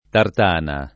tartana [ tart # na ]